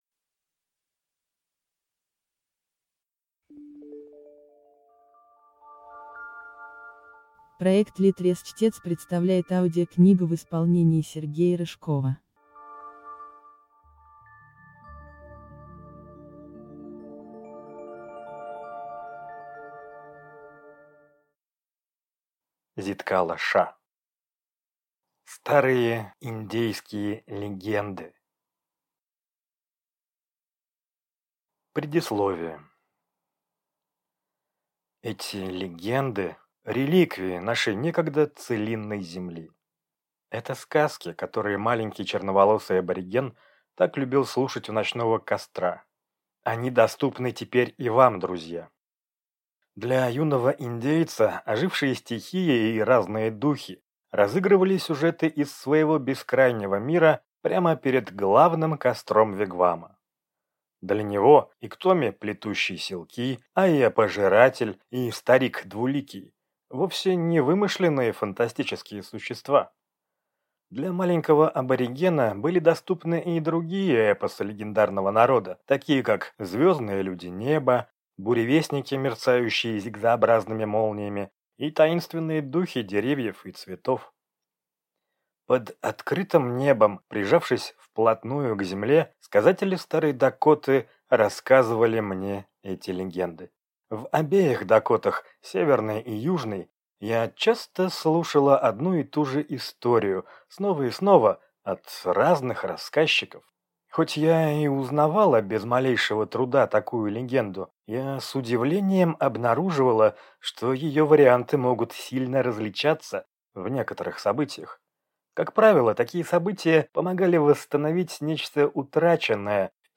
Аудиокнига Старые индейские легенды | Библиотека аудиокниг